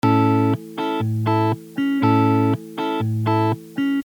پکیج ریتم و استرام گیتار الکتریک ایرانی
دموی صوتی ریتم دو چهارم :